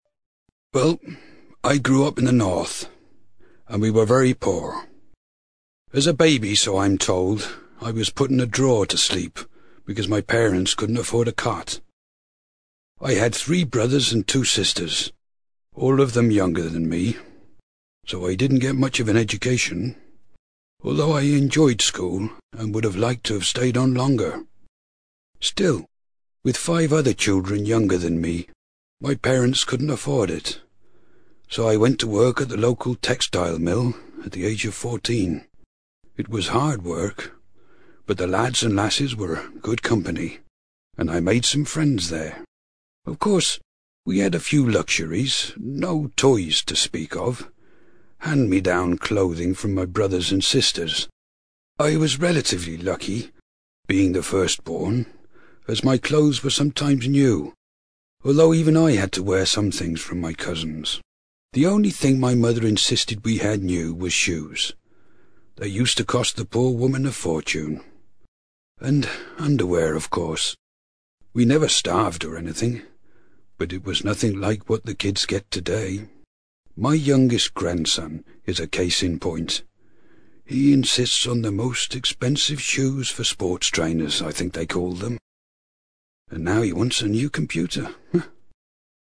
ACTIVITY 30: You are going to hear an old man reminiscing about his childhood.